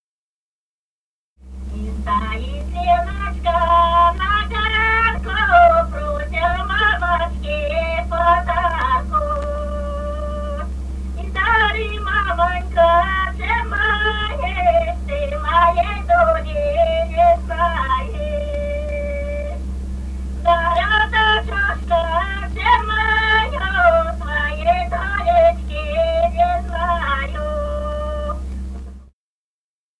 Русские народные песни Красноярского края.
«И стоит Леночка на ганку» (свадебная). с. Дзержинское Дзержинского района.